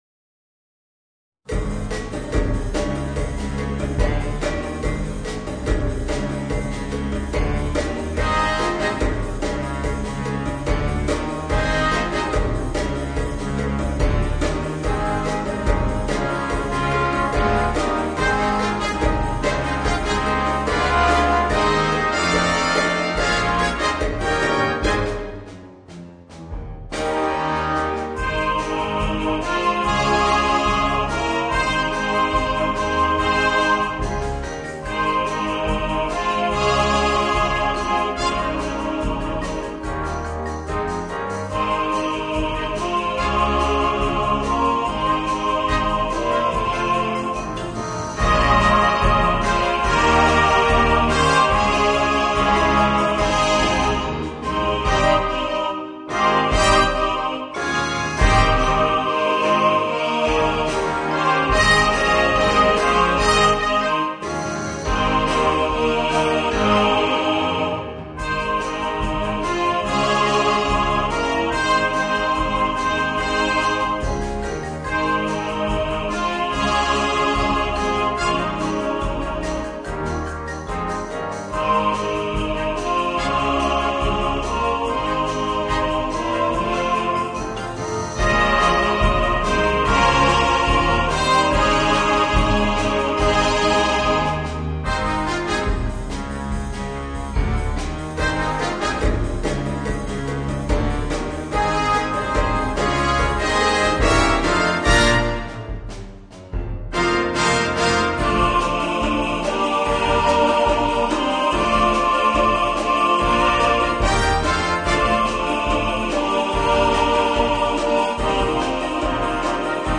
Voicing: Big Band and Chorus